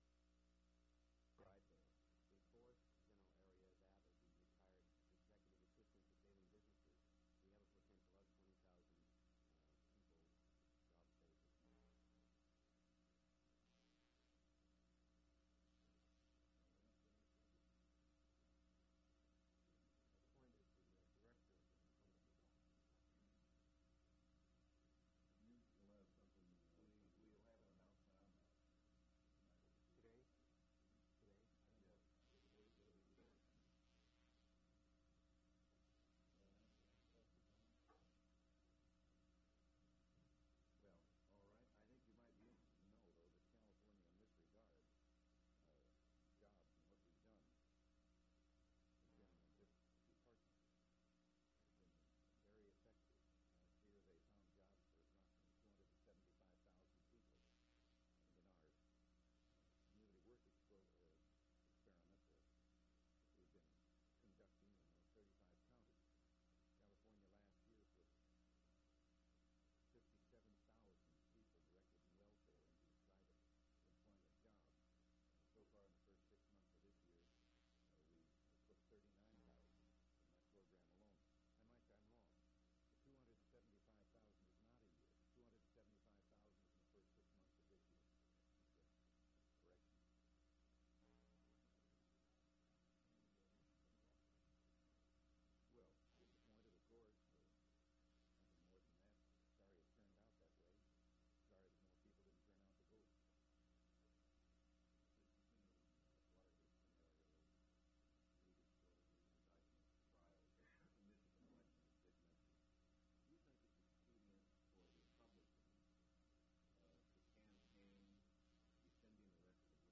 Governor Ronald Reagan News Conference
Audio Cassette Format.